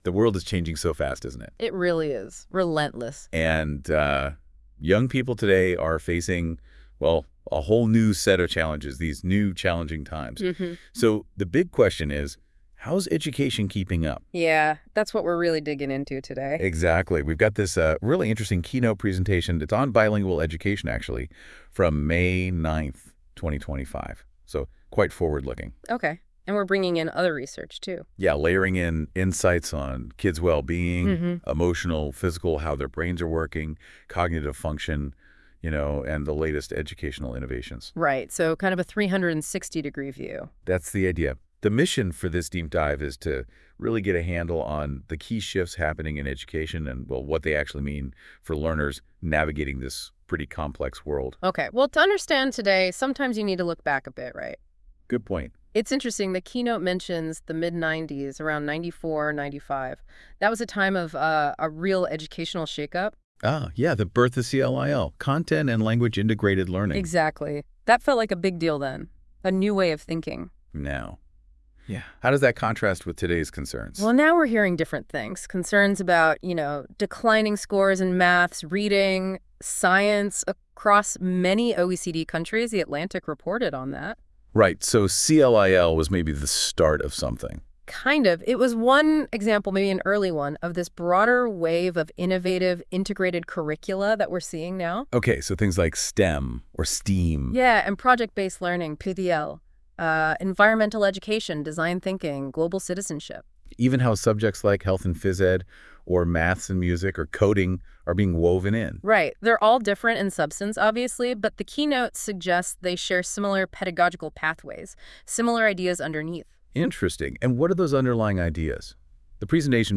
This AI-generated podcast (16 mins) summarizes a presentation, CLIL, Zeitgeist, and Learning Spaces in May 2025.